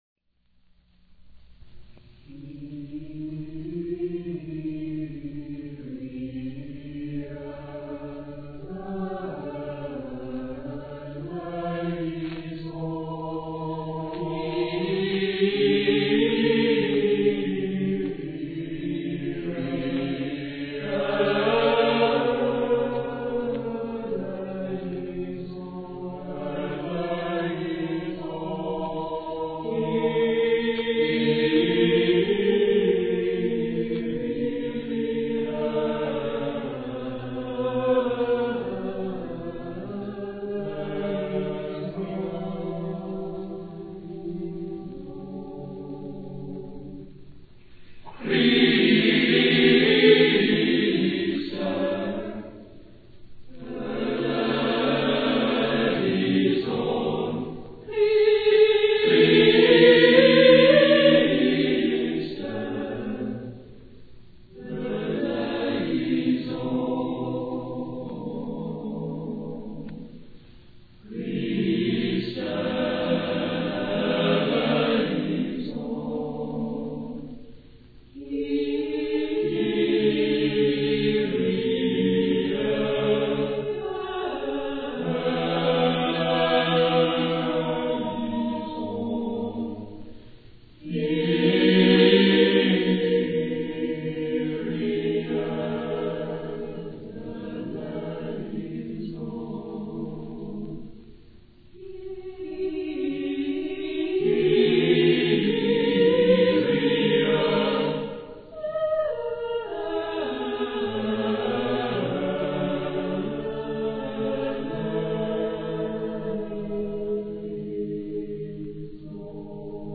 A Capella